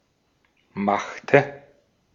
Ääntäminen
Etsitylle sanalle löytyi useampi kirjoitusasu: Machte machte Ääntäminen Tuntematon aksentti: IPA: /maχtə/ Haettu sana löytyi näillä lähdekielillä: saksa Käännöksiä ei löytynyt valitulle kohdekielelle.